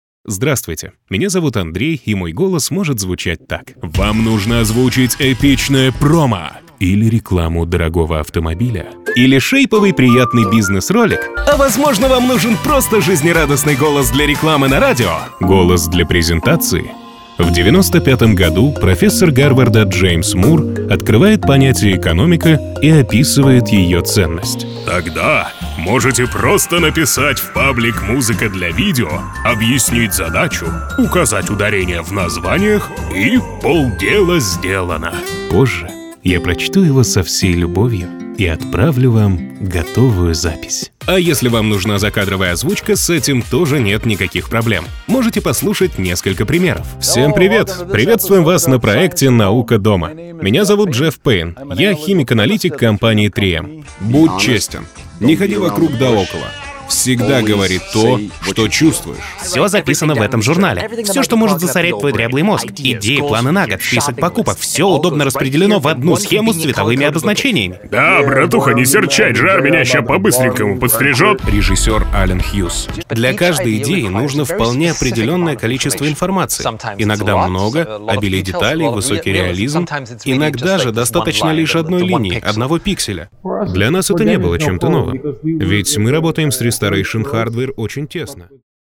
Дикторские голоса (Озвучка)
Мужские